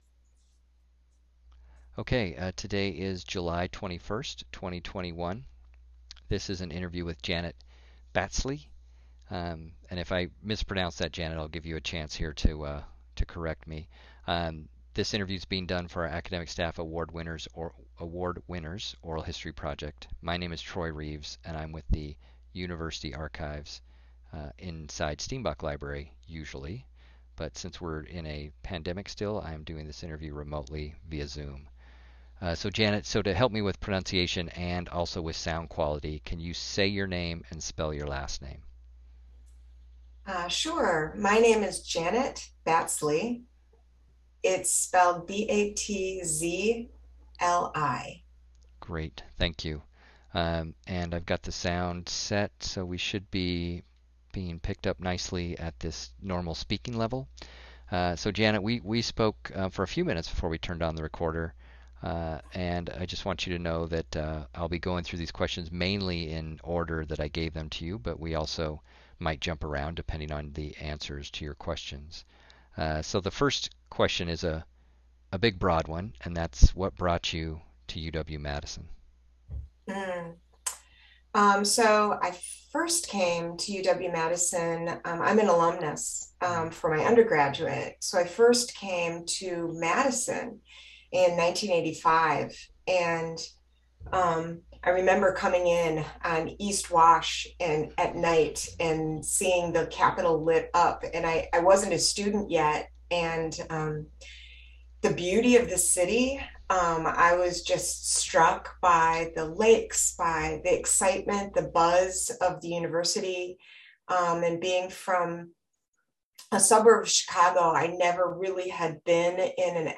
University of Wisconsin-Madison Oral History Program